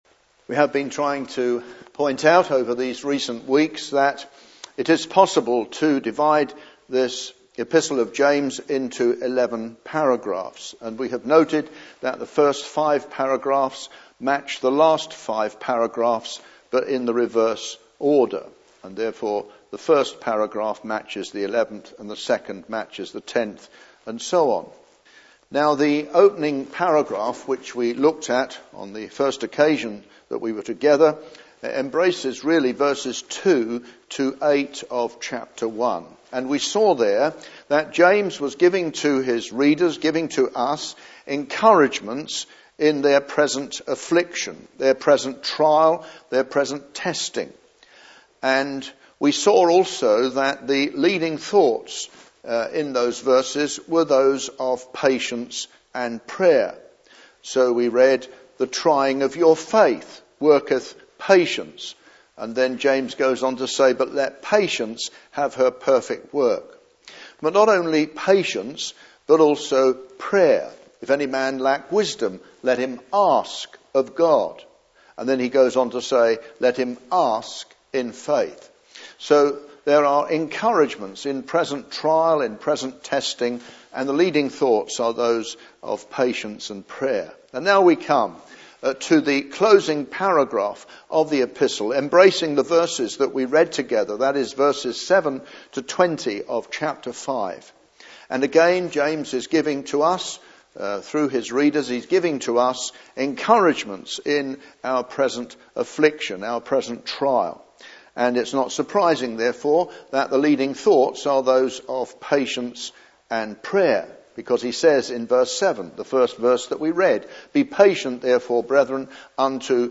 He warns against the use of expressions such as “Good heavens” and “Goodness Me” in the passage on ‘swearing’ (Message preached 11th June 2009)